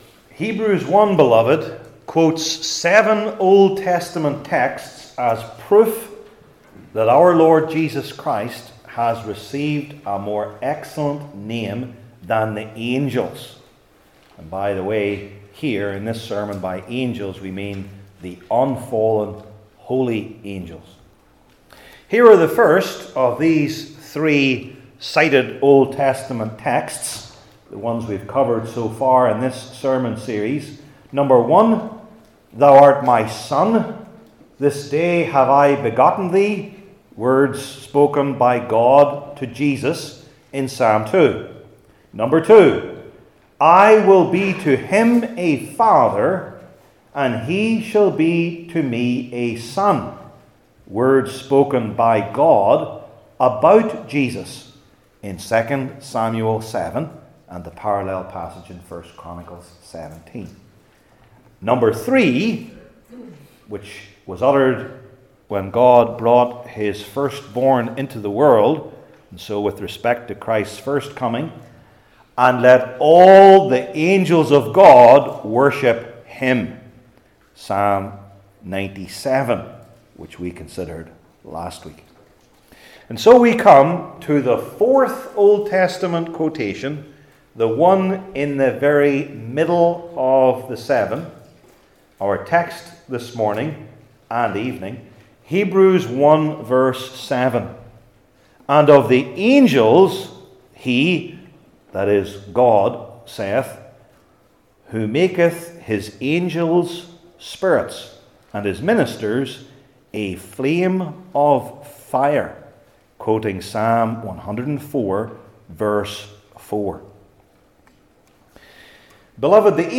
Passage: Hebrews 1:7 Service Type: New Testament Sermon Series I. Their Superiority to Man II.